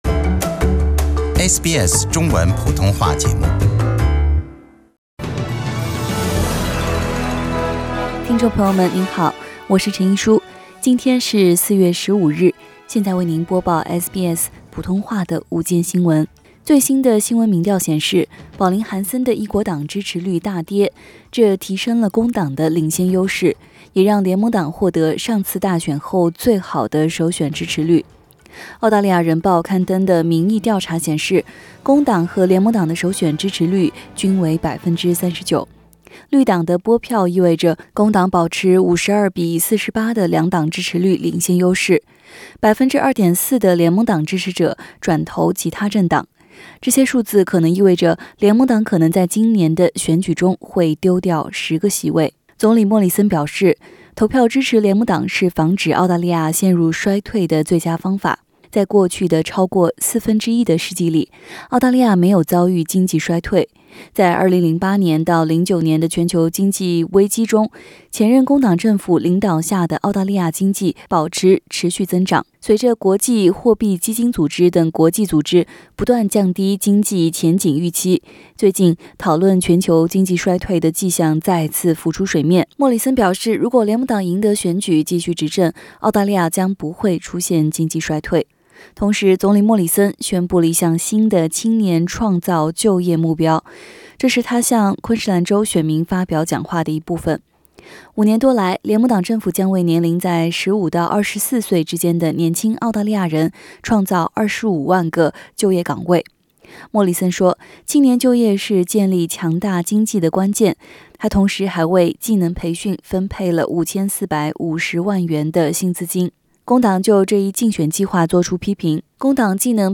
SBS午间新闻（4月15日）